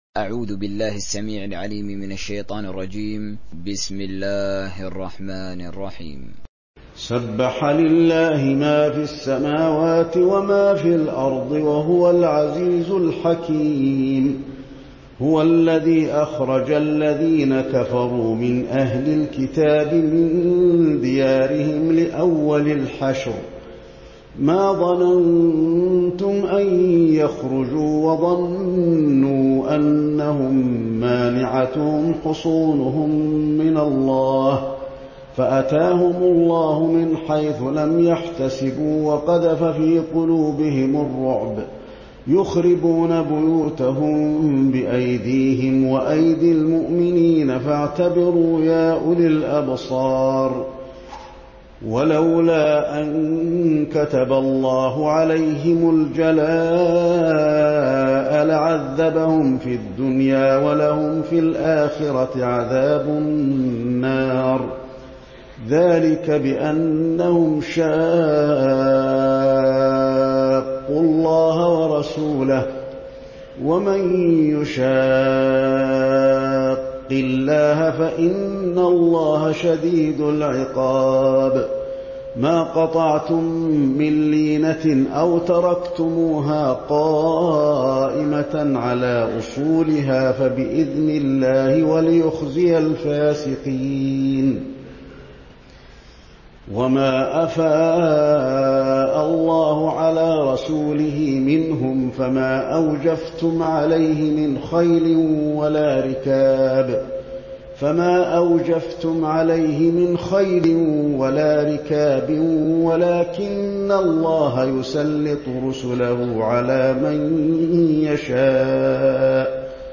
تحميل سورة الحشر حسين آل الشيخ تراويح